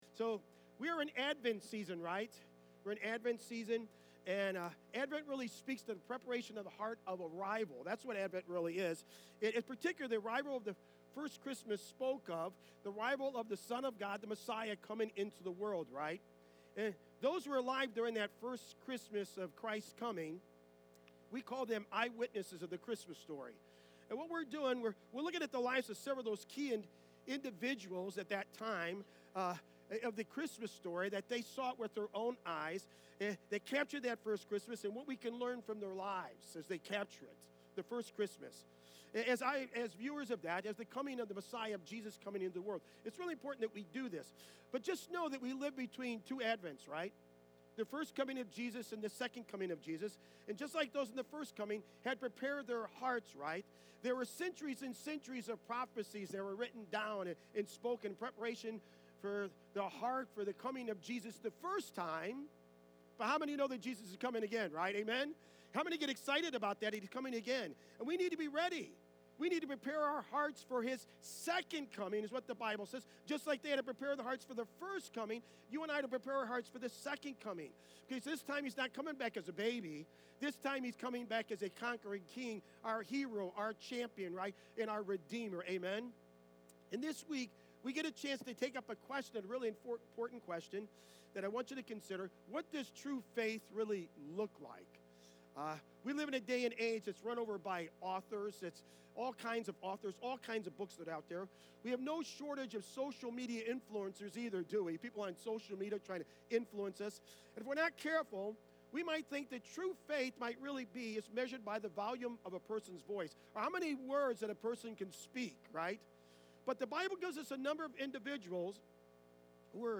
12-8-24-sermon.mp3